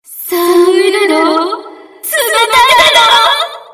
棲艦語音5